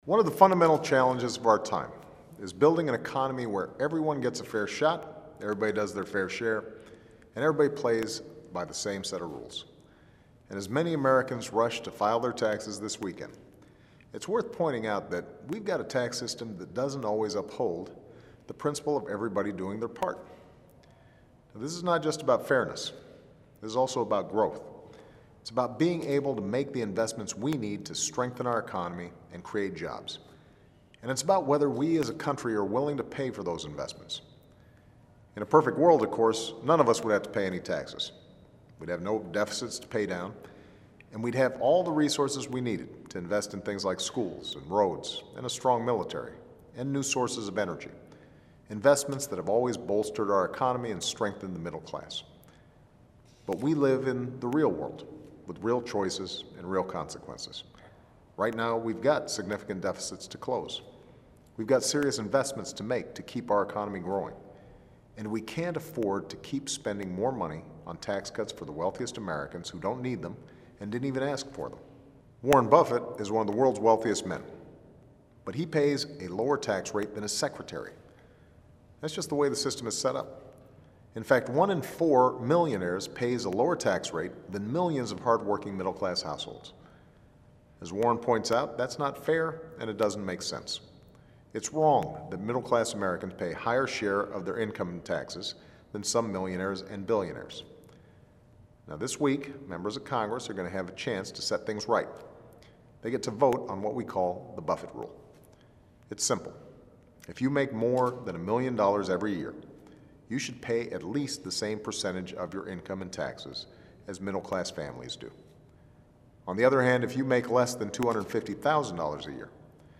演讲听力材料04.15
Remarks of President Obama